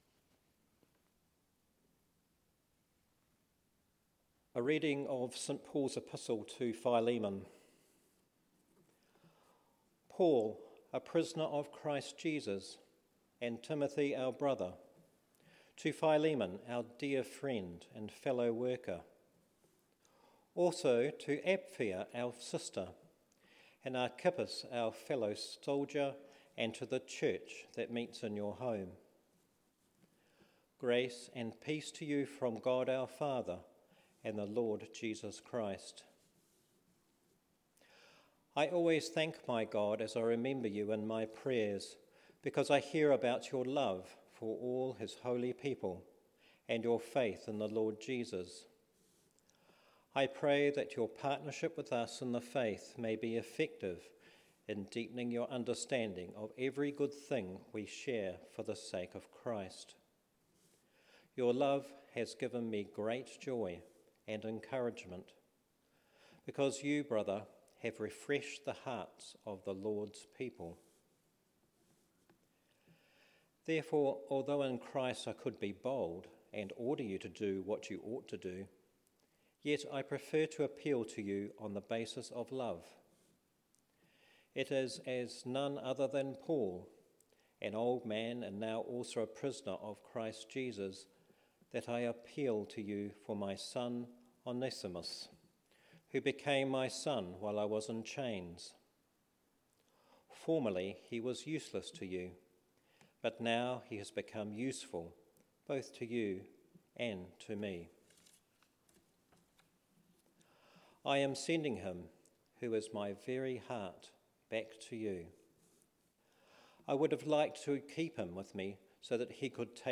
A homily for Evensong